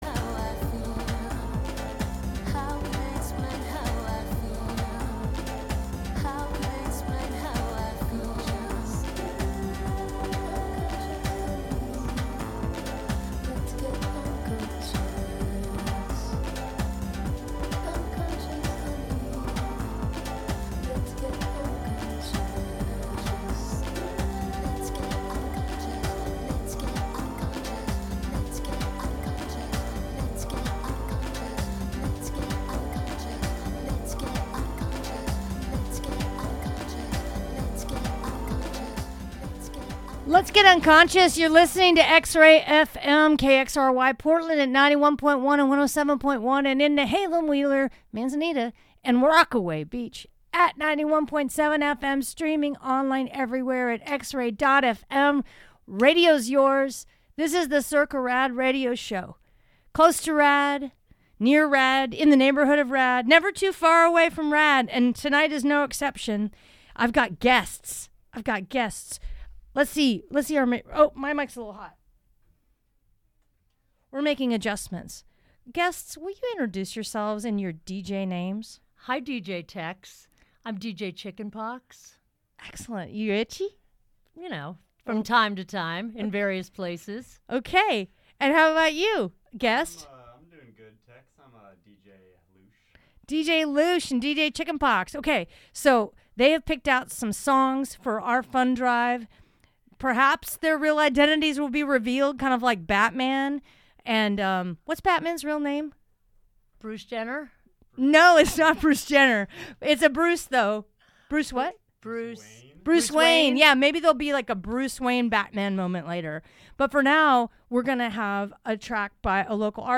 Join the hunt for lost gems and new treasures every Wednesday at 7pm. We serve indie rock, global psychedelica, lo-fi, art punk, a particular sort of americana, Portland-centric, international pop, folkishness, and real rock and good radio.